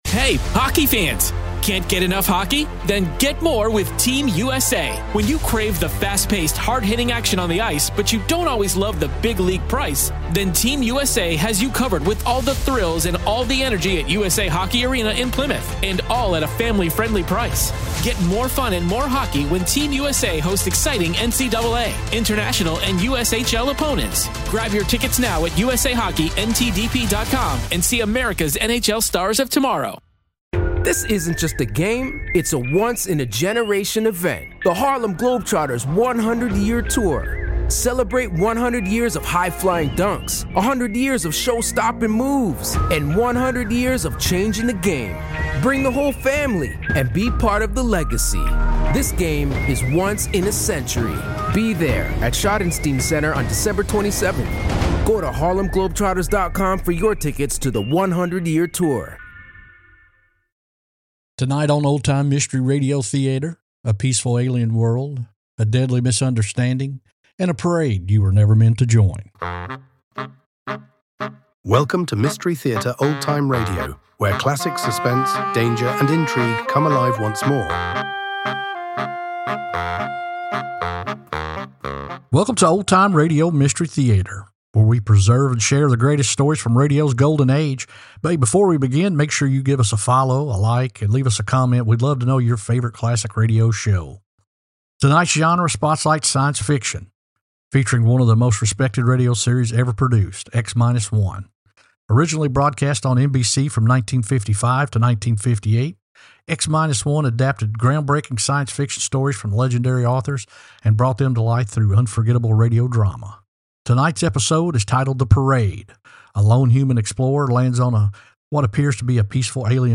Originally broadcast on NBC, X Minus One is widely regarded as one of the finest sci-fi radio drama series ever produced. This episode tells the haunting story of a human explorer who encounters an alien culture where a simple misunderstanding leads to deadly consequences.